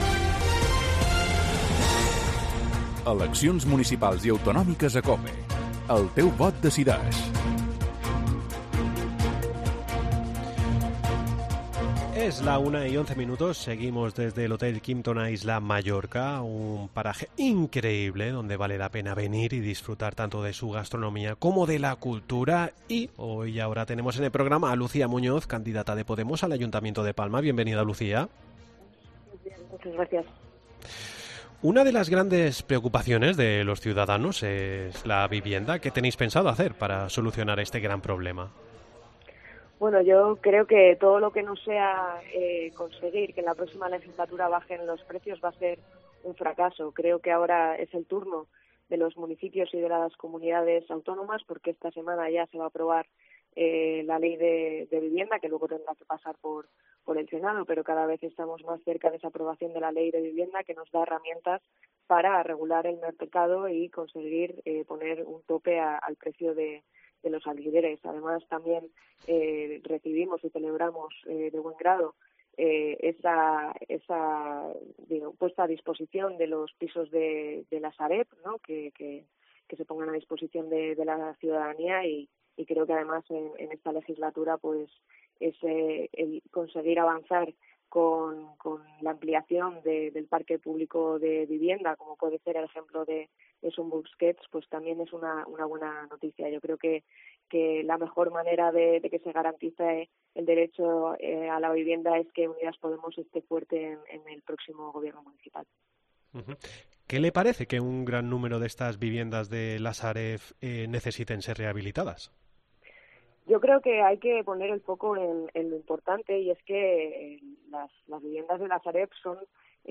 AUDIO: Continuamos con las entrevistas electorales.